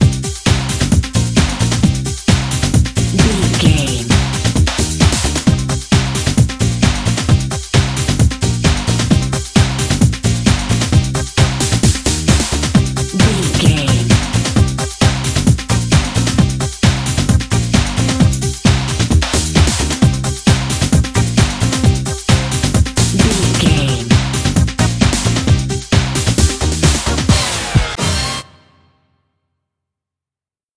Ionian/Major
synthesiser
drum machine